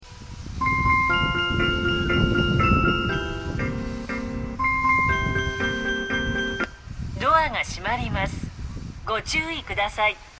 スピーカーは小ボスが設置されており音質は非常にいいです。
発車メロディー途中切りです。